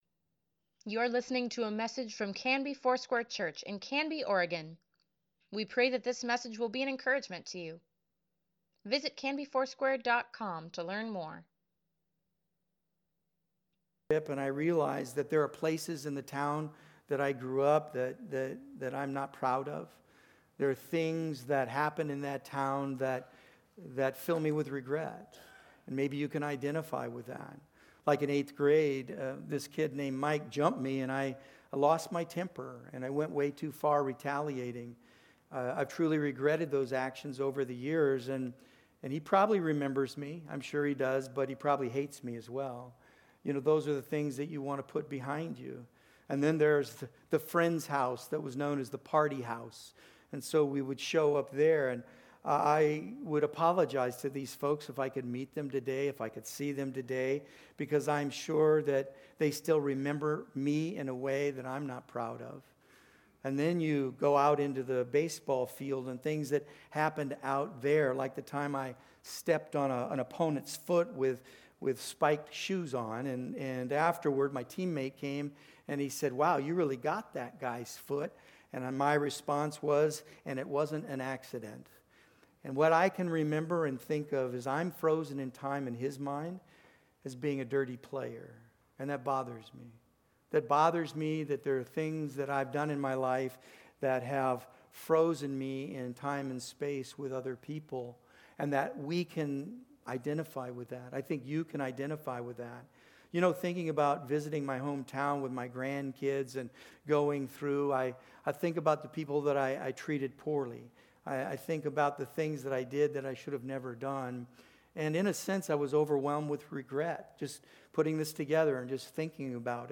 Easter Sunday Sermon | March 31, 2024